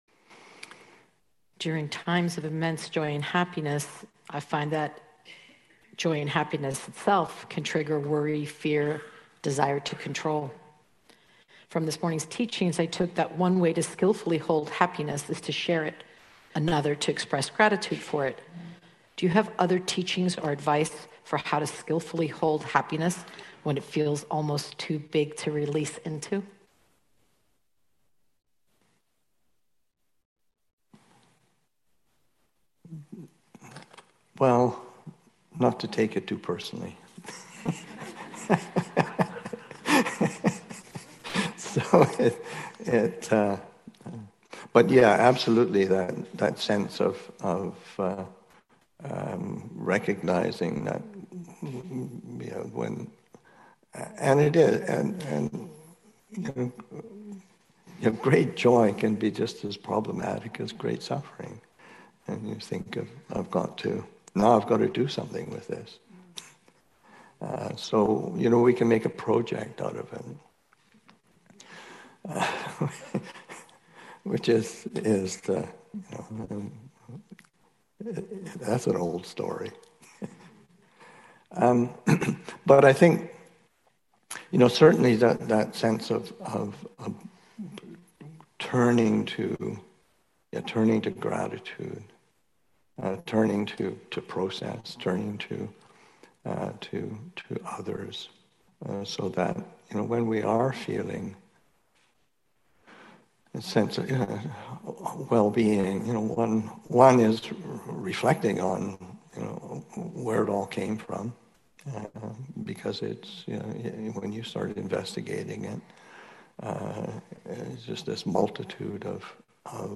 Spirit Rock Daylong, Aug. 20, 2023